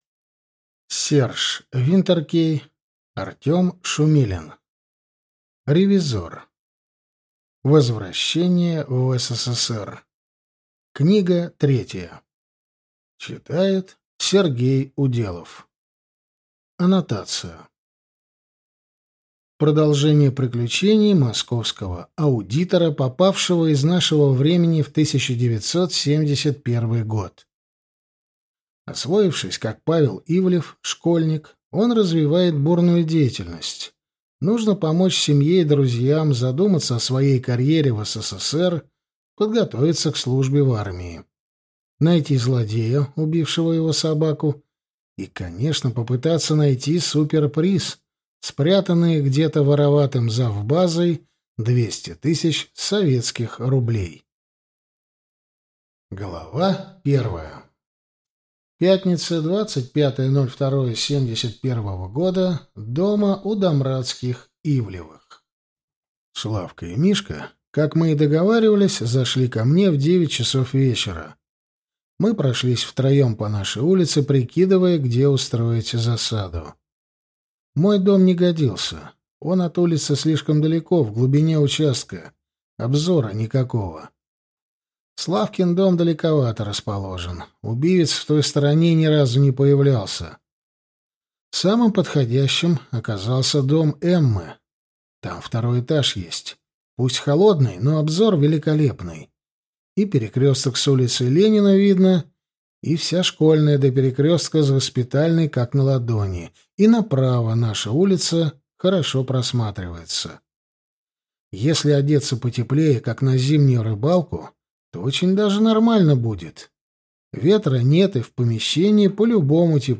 Аудиокнига Ревизор: возвращение в СССР 3 | Библиотека аудиокниг